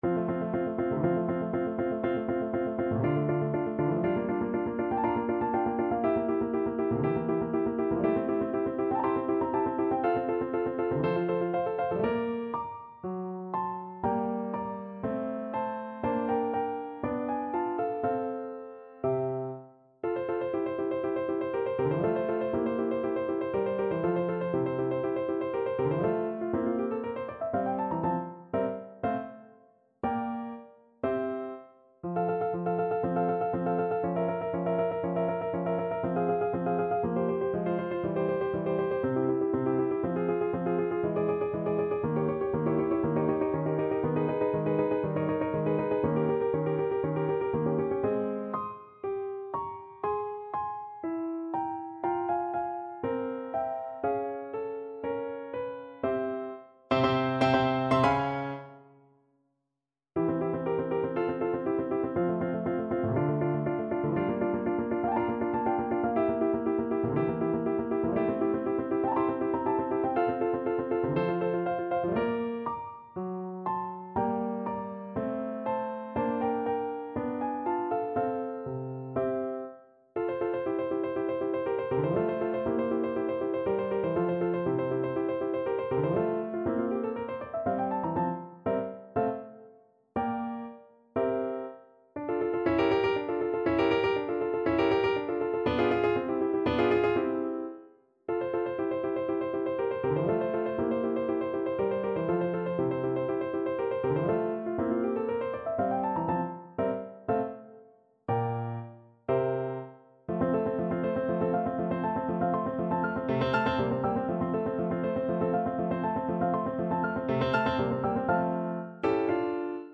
4/4 (View more 4/4 Music)
=120 Andante
Classical (View more Classical Flute Music)